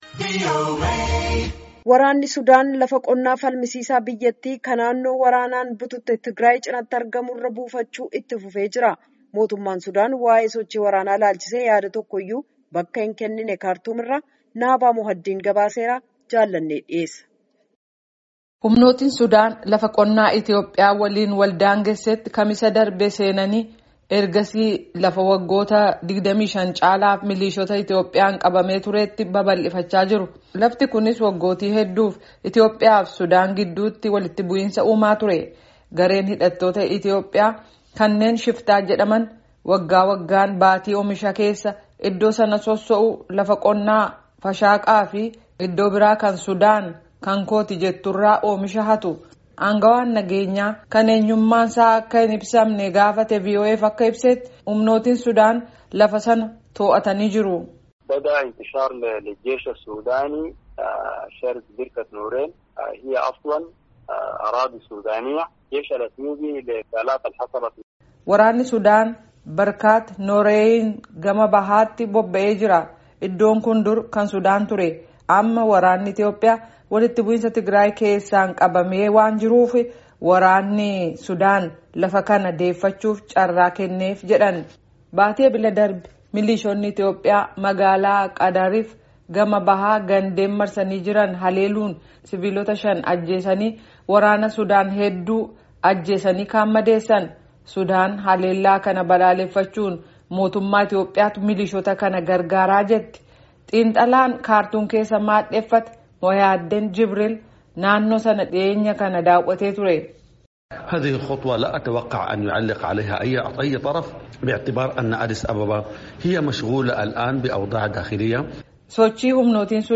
Gabaasa Guutuu Caqasaa